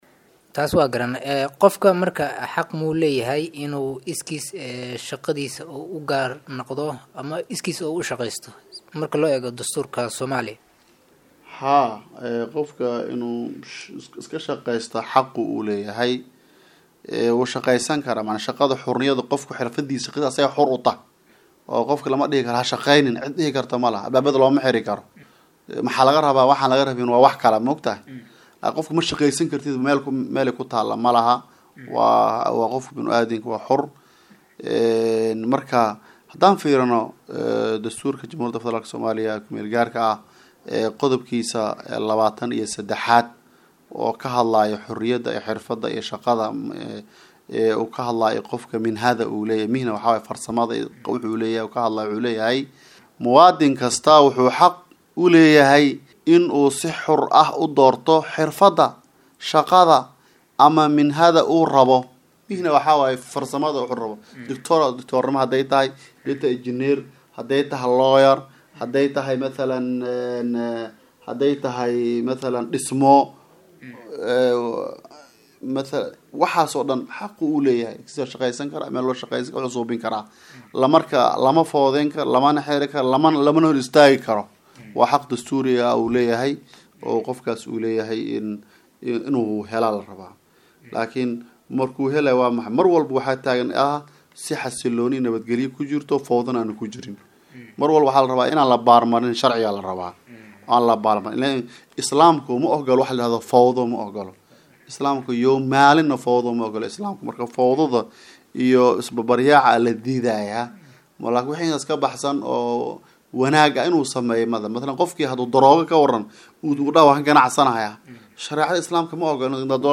Waraysi-Xorriyadda-qofka-qaybta-2aad.mp3